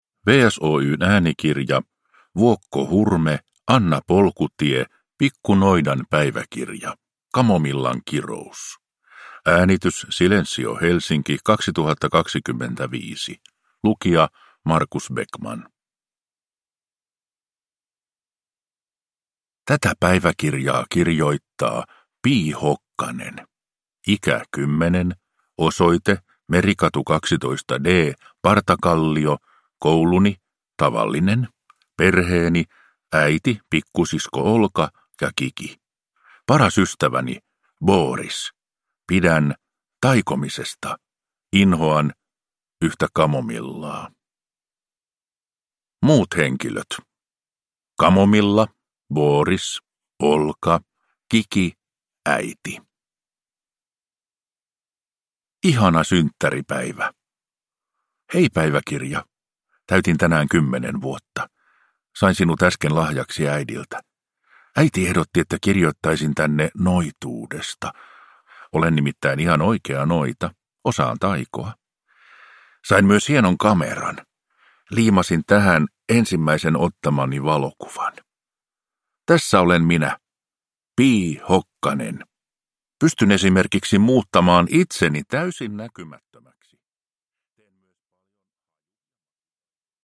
Pikku noidan päiväkirja: Kamomillan kirous – Ljudbok